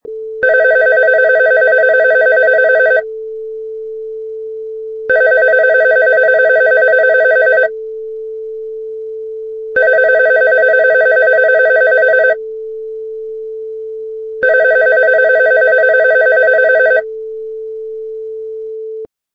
Wav: Telephone 4 Ring 9
Four successive rings on a telephone
Product Info: 48k 24bit Stereo
Category: Electronics / Telephones
Try preview above (pink tone added for copyright).
Telephone_4_Ring_9.mp3